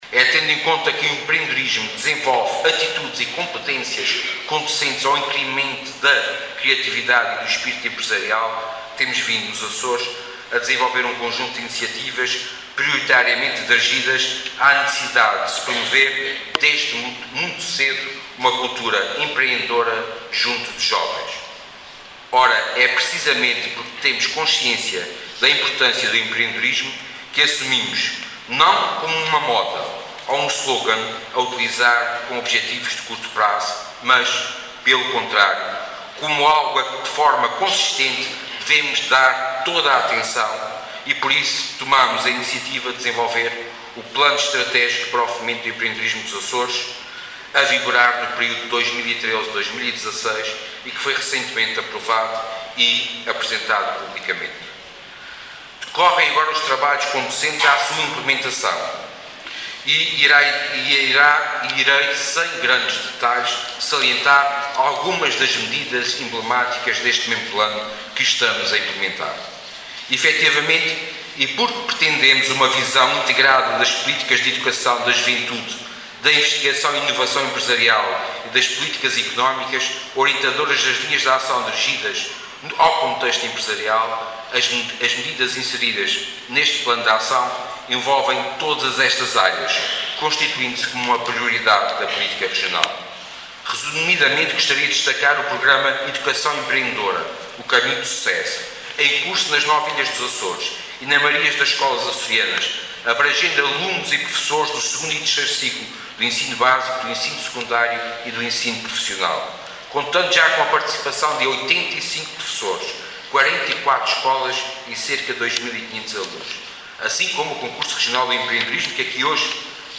Sérgio Ávila, que falava na sessão de encerramento da Gala do Empreendedor 2012, referiu que, apesar de se estar a atravessar um momento difícil, “onde as dúvidas sobre o futuro tendem a não ser dissipadas”, a verdade é que o Povo dos Açores sempre enfrentou os seus desafios de forma exemplar e “demonstrou uma elevada capacidade de adaptação às circunstâncias de cada momento”.